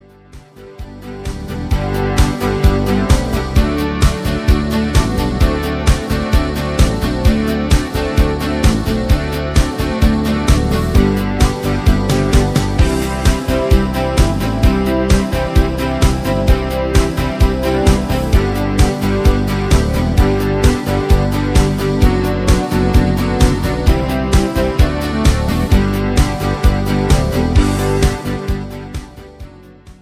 im Discofox Rhytmus